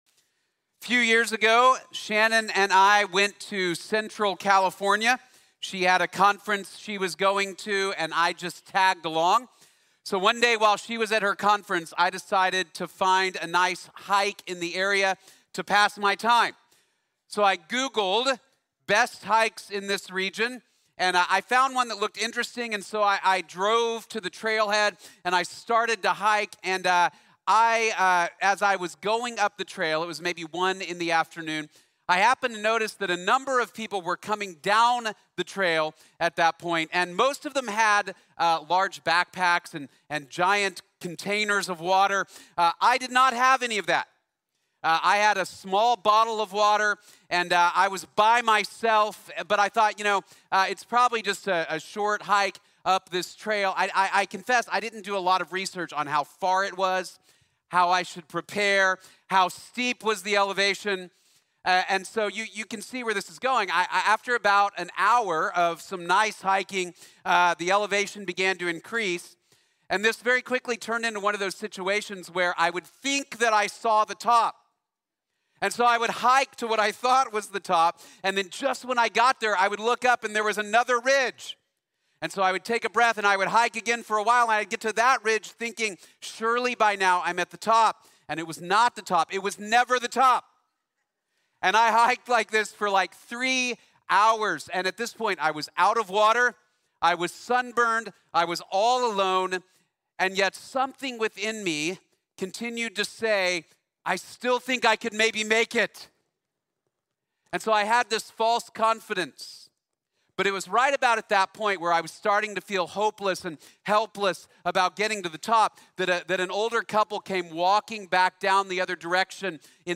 God’s Righteousness for Free | Sermon | Grace Bible Church